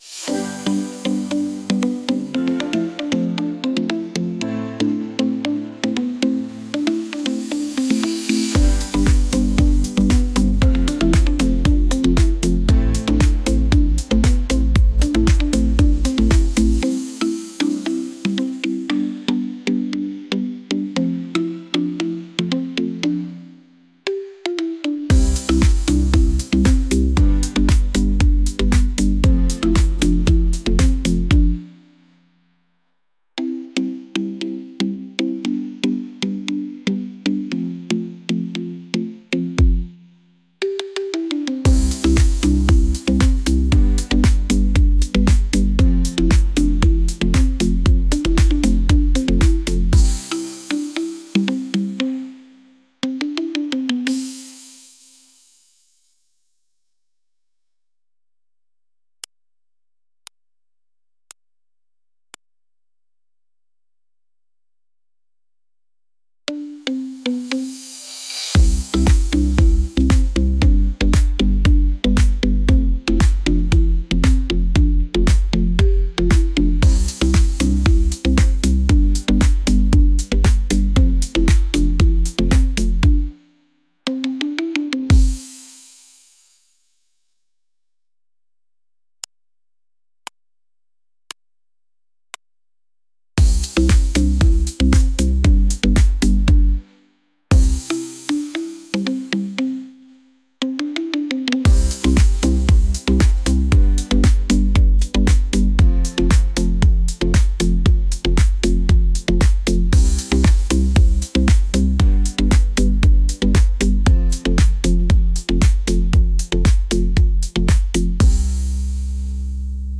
upbeat | pop